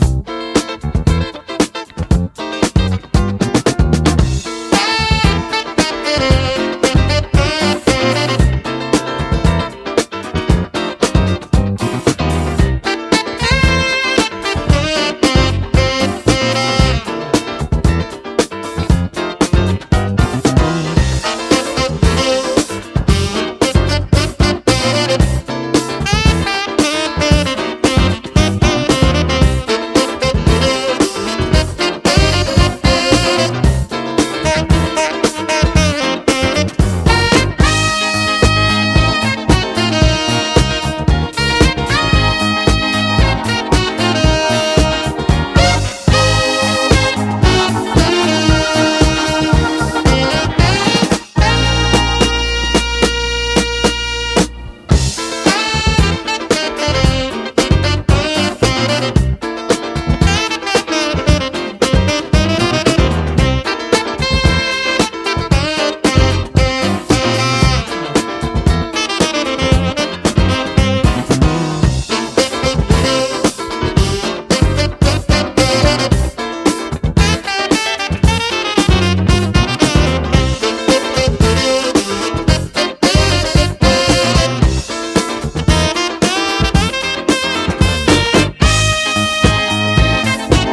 SOUL / SOUL / 70'S～